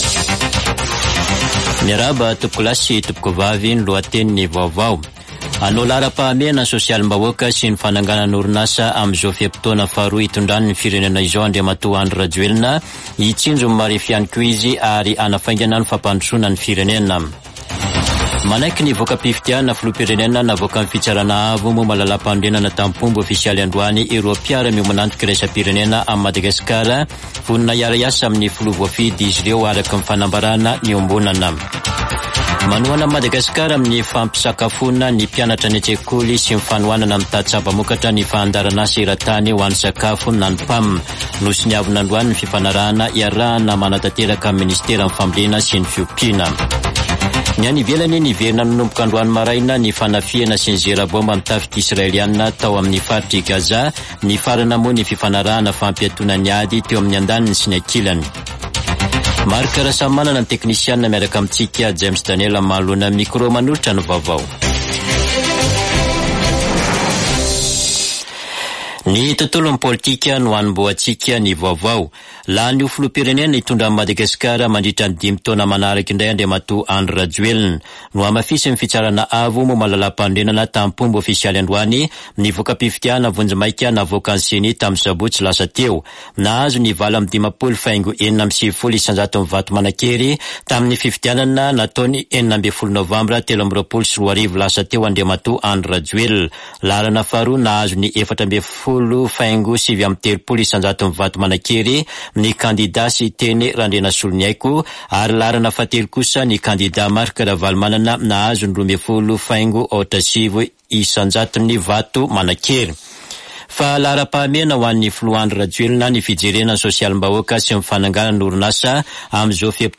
[Vaovao hariva] Zoma 1 desambra 2023